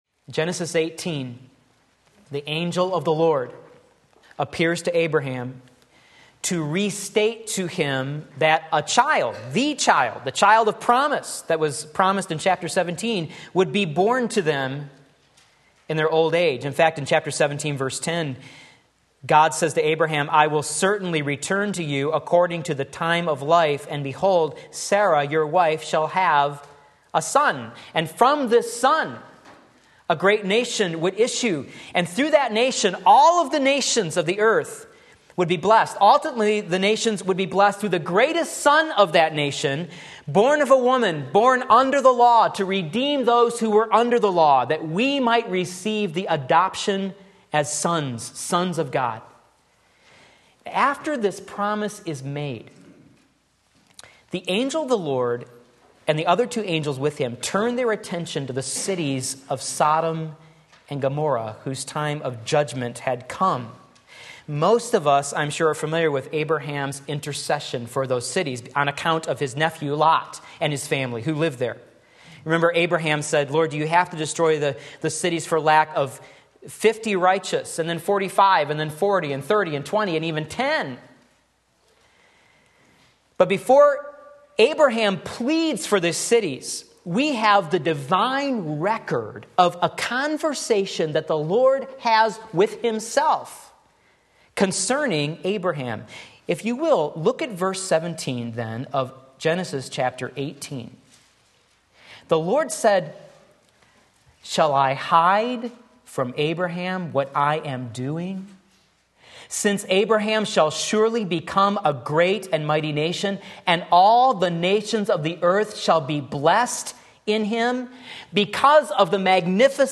Sermon Link
Discipling the Next Generation Genesis 18:19 Sunday Morning Service